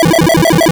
toss.wav